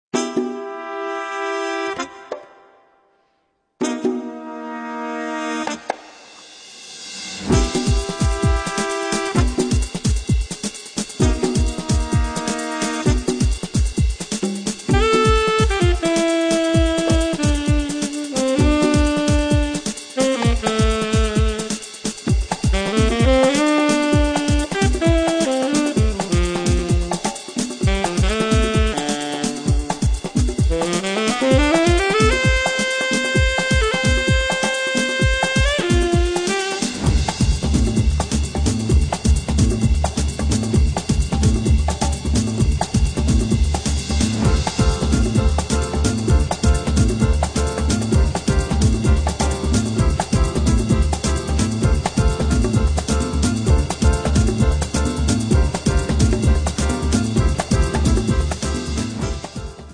Double Bass
Drums